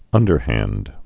(ŭndər-hănd)